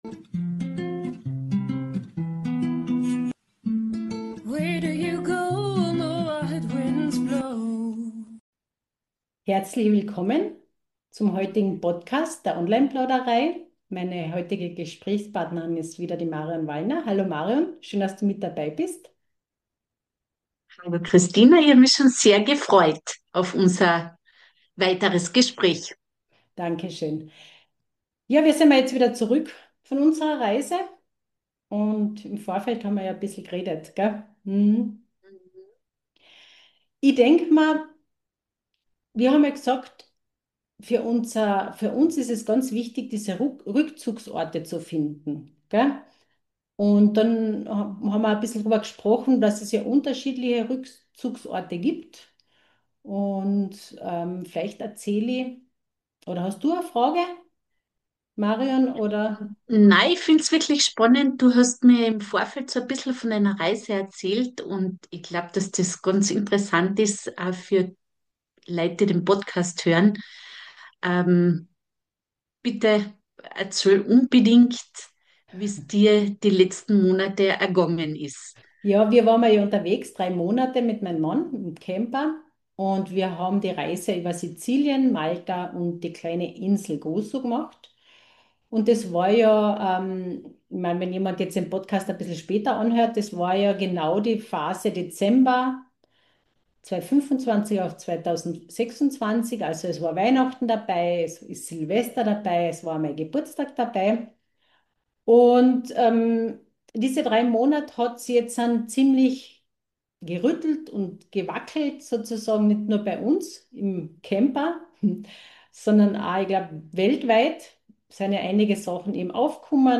Online Plauderei – inspirierende Gespräche für persönliche Entwicklung und energetische Arbeit im Online-Zeitalter.